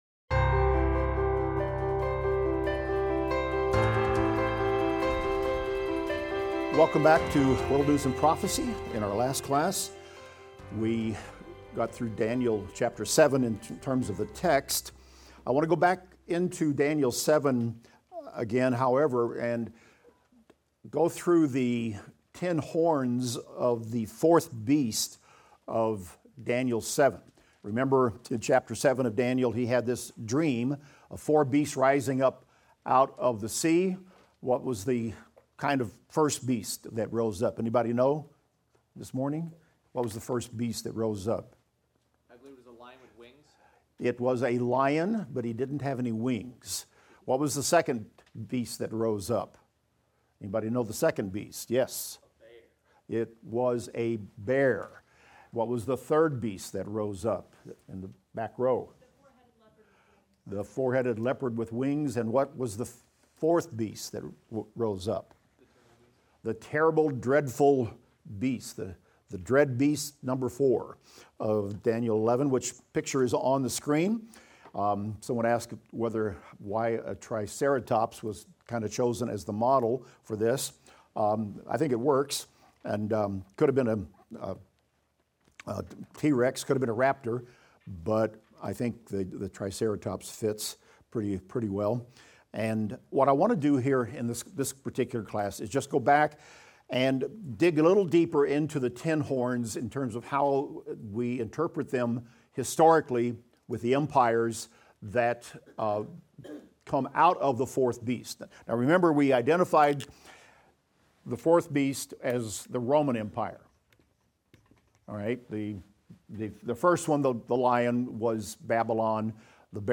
Daniel - Lecture 11 - audio.mp3